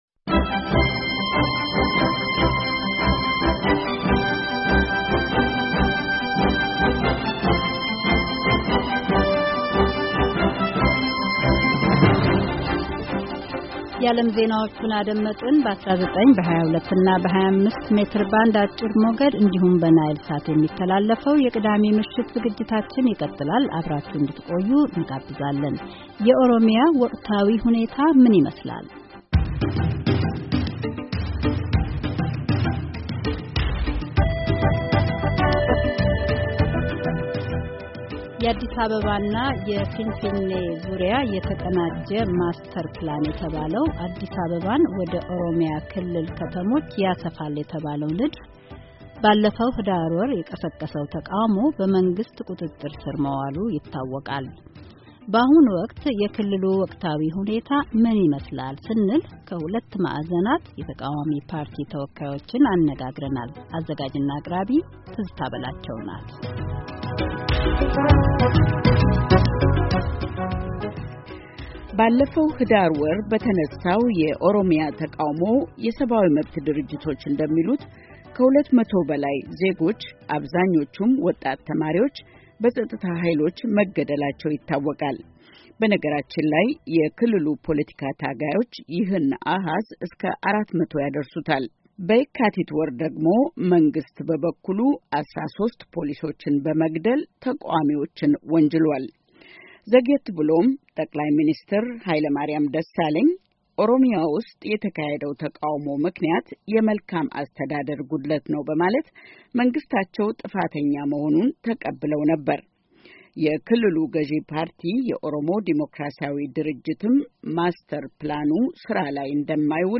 የአዲስ አበባና የፊንፊኔ ዙሪያ የተቀናጀ ማስተር ፕላን ንድፍን በመቃወም በኦሮሚያ የተለያዩ ከተሞች በሕዳር ወር መግቢያ የቀሰቀሰው ተቃውሞ መንግሥት በቁጥጥር ስር አድርጌዋለሁ ማለቱ ይታወቃል።ከዚህ በተቃራኒው ደግሞ በክልሉ የሚገኙ ነዋሪዎች ክልሉ በስምንንት ወታደራዊ ቀጣናዎች ተከፋፋሎ እንደሚገኝ ይናገራሉ። በአሁኑ ወቅት የክልሉ ወቅታዊ ኹኔታ ምን ይመስላል? ከሁለት ማእዘናት የተቃዋሚ ፓርቲ ተወካዮችን